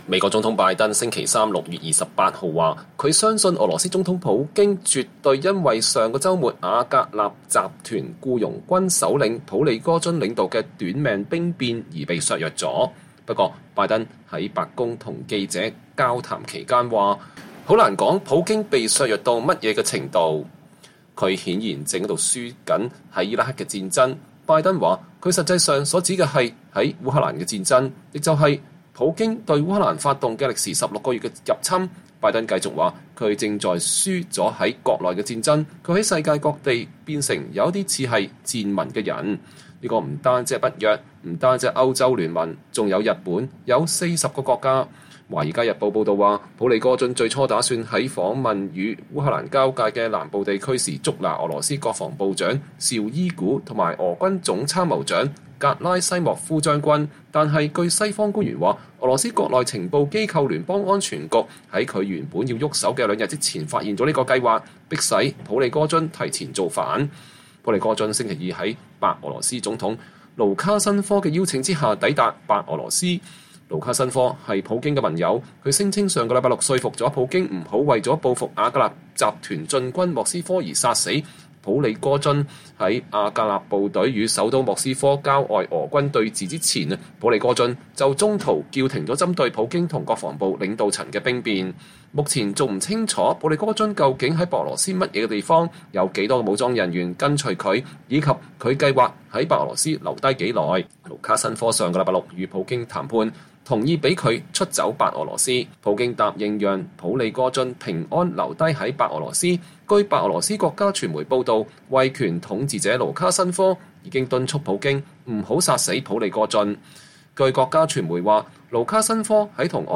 拜登總統在白宮南草坪登上“海軍陸戰隊一號”專用直升機之前與媒體交談。(2023年6月28日)